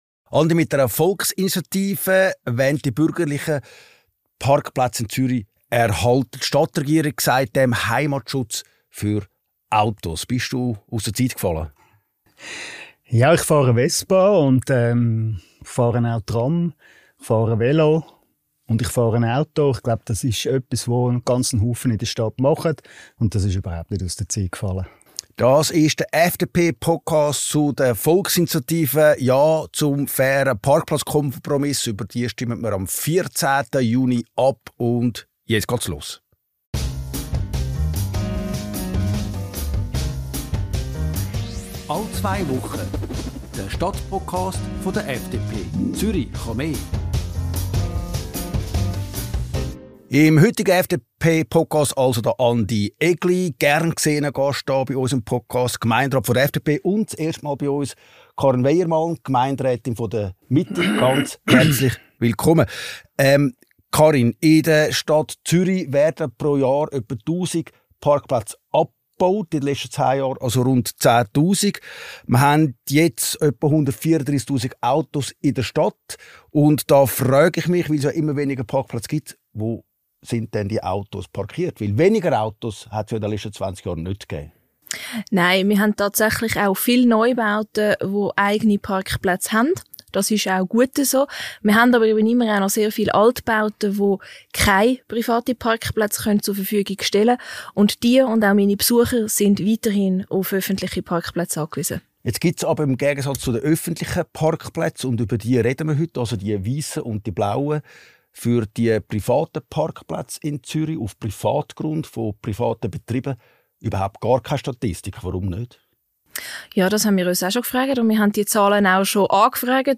Obwohl der Bestand der Autos seit Jahren der gleiche bleibt. Am 14. Juni ist nun eine Kompromissvorlage an der Stimmurne, welche Parkplätze für alle Verkehrsteilnehmenden entsprechend den Bedürfnissen in den Quartieren will. FDP-Gemeinderat Andy Egli und Mitte-Gemeinderätin Karin Weyermann zeigen im Stadtpodcast auf, dass dieser Kompromiss für alle sinnvoll ist.